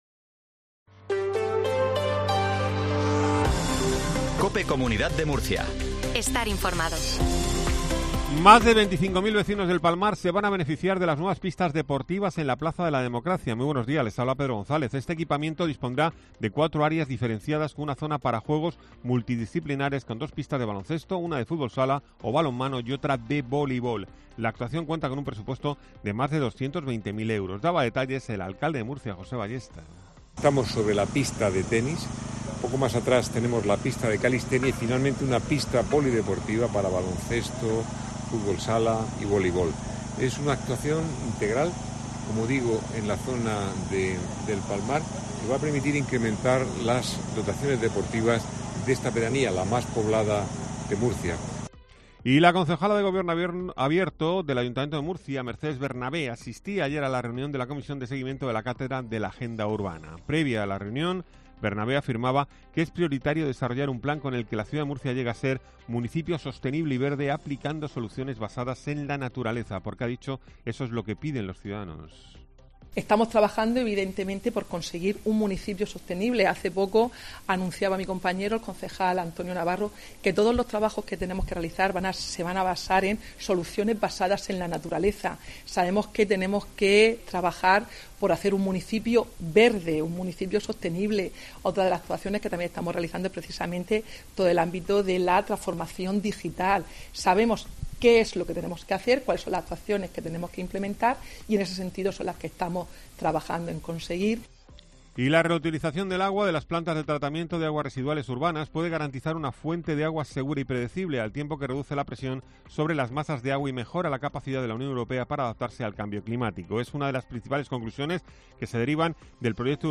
INFORMATIVO MATINAL REGION DE MURCIA 0820